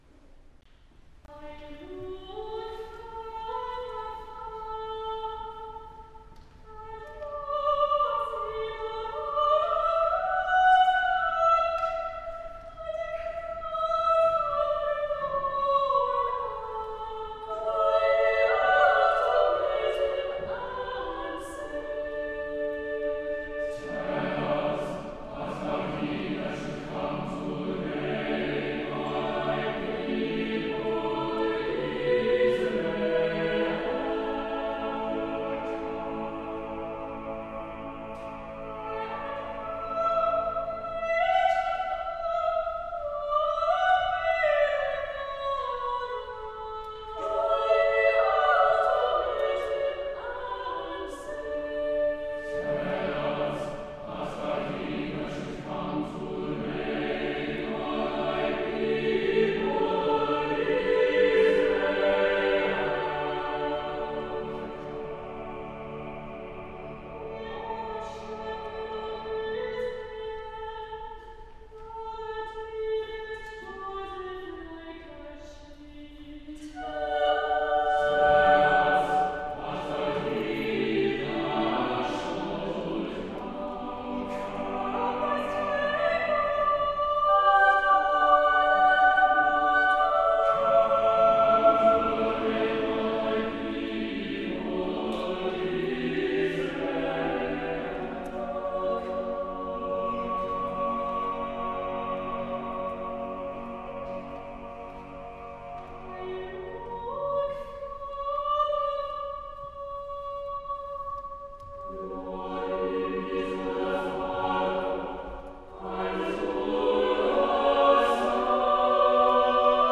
TRINITY COLLEGE CAMBRIDGE ADVENT CAROL SERVICE 2019